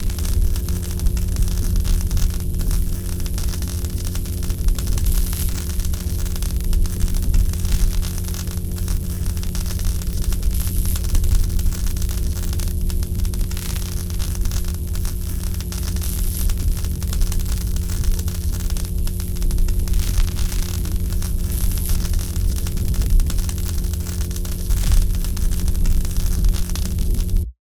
electra_idle.ogg.bak